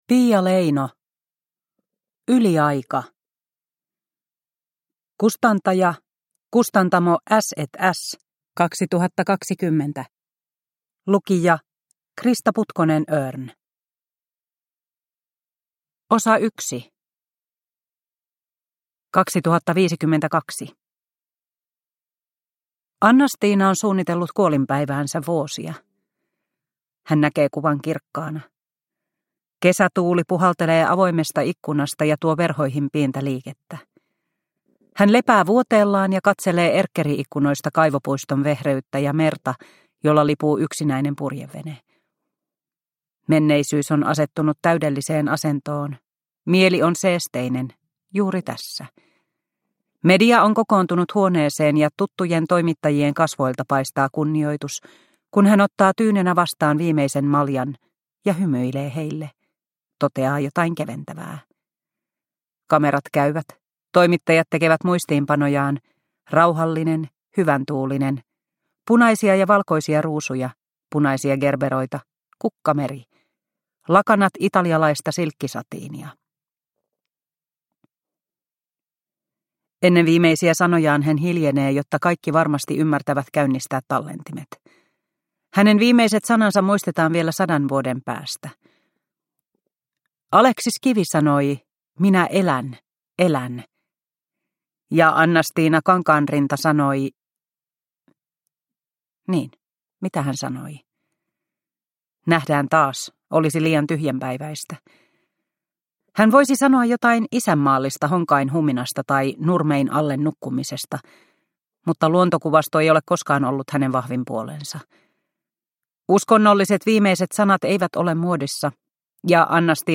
Yliaika – Ljudbok